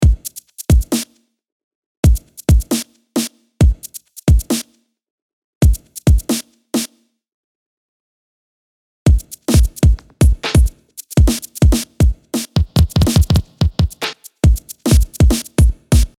Da, welcher part grooved und welcher nicht verrate ich aber nicht 8)